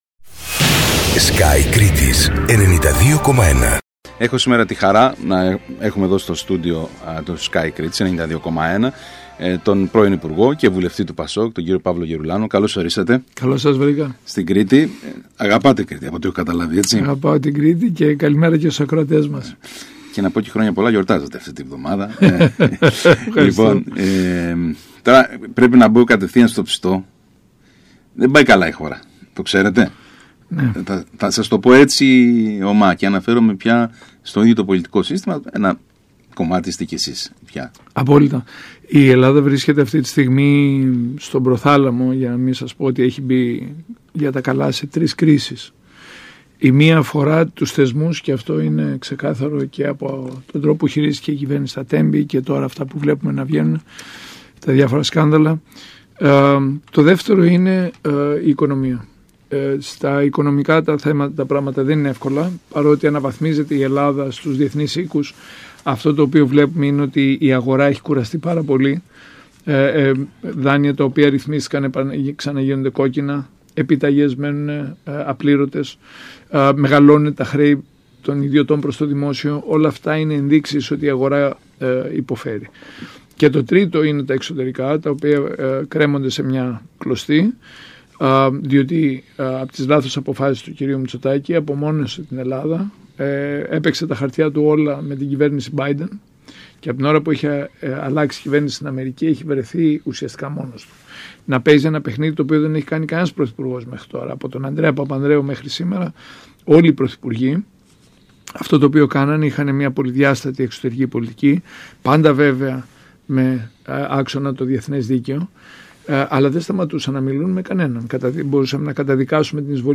μιλώντας στον ΣΚΑΙ Κρήτης
ο πρώην υπουργός και βουλευτής του ΠΑΣΟΚ Παύλος Γερουλάνος